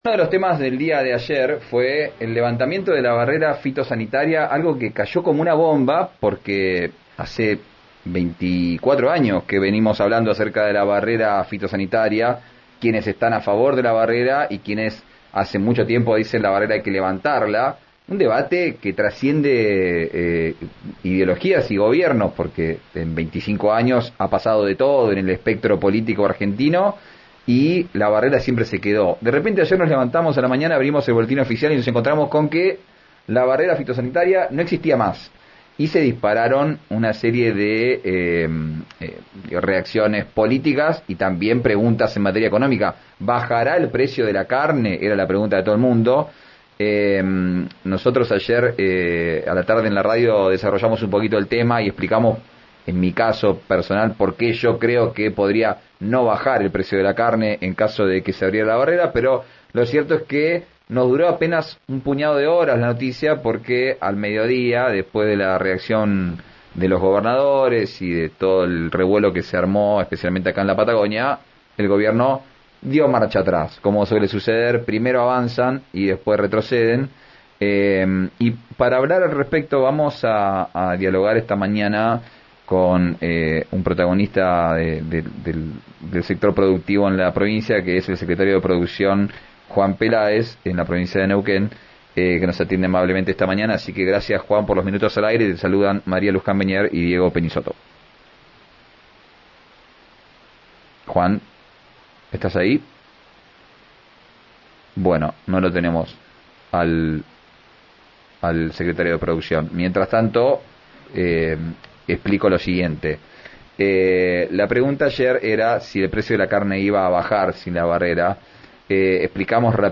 Escuchá al secretario de Producción, Juan Peláez en RIO NEGRO RADIO